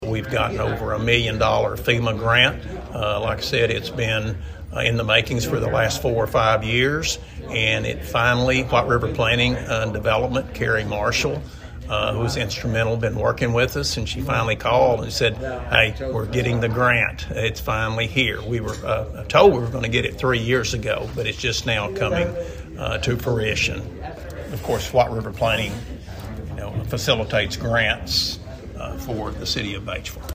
Listen below to Elumbaugh’s comments to White River Now for more information on the drainage grant:
white-river-now-batesville-mayor-rick-elumbaugh-on-wrpdd-grant-for-drainage-project.mp3